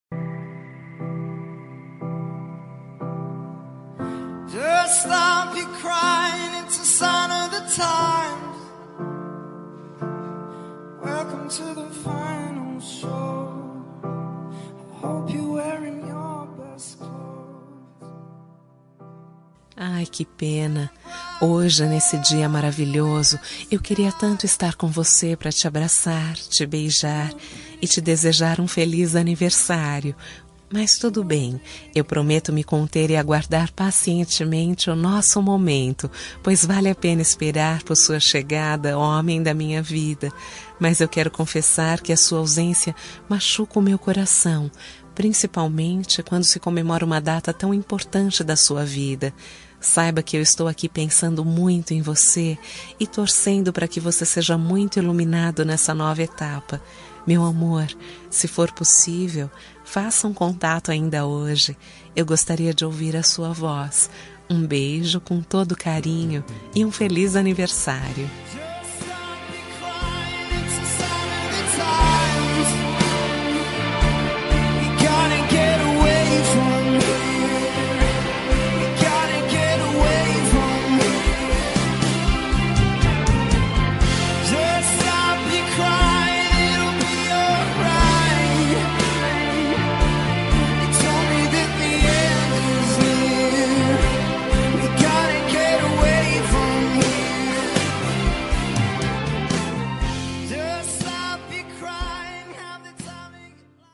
Telemensagem Para Amante – Voz Feminina – Cód: 6756